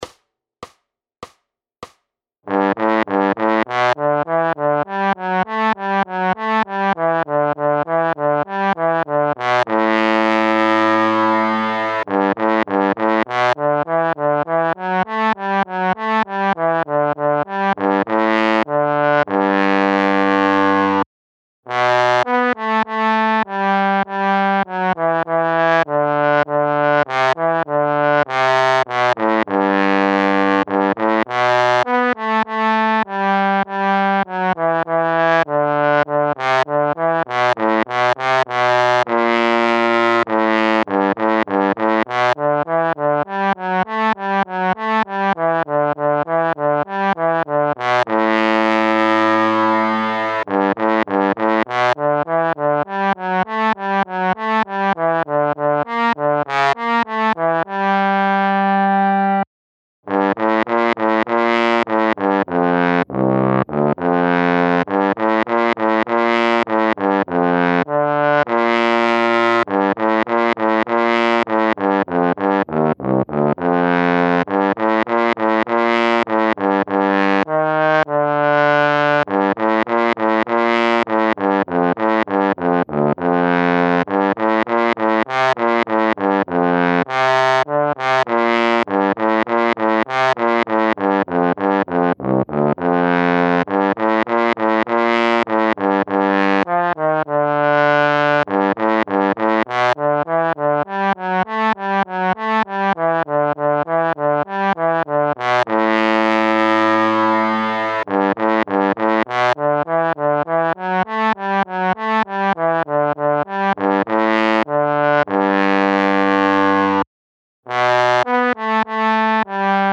Noty na pozoun, trombon.
Aranžmá Noty na pozoun, trombon
Hudební žánr Klasický